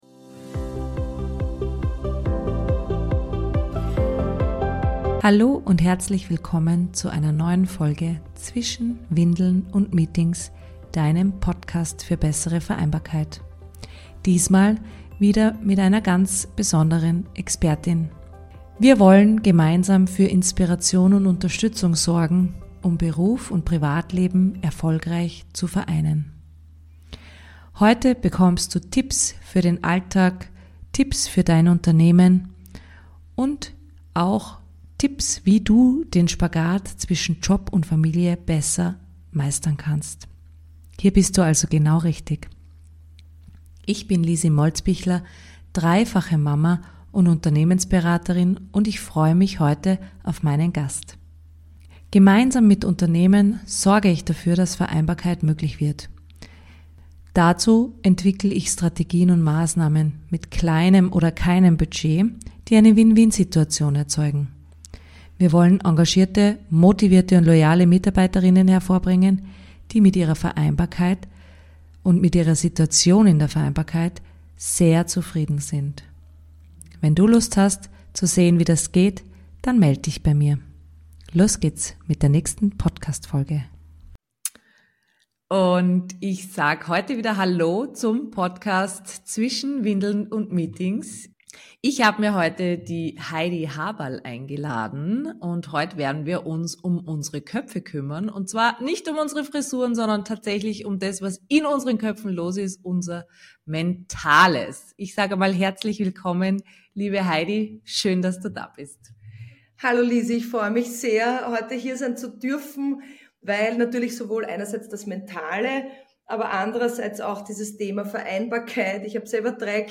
#17: Interview